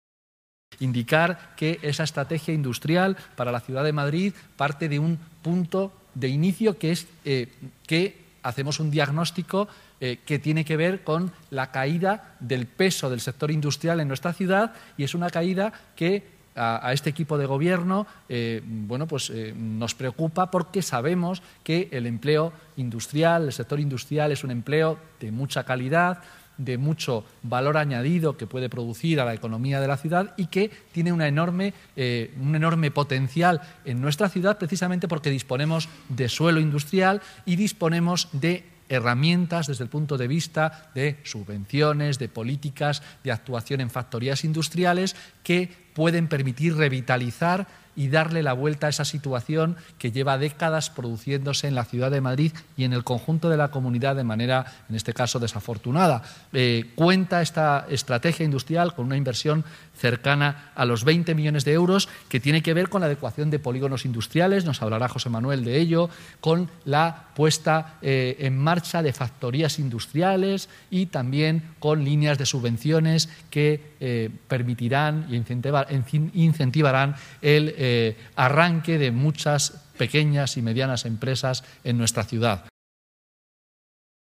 Presentación estrategia
Carlos Sánchez Mato habla sobre la estrategia industrial para la ciudad de Madrid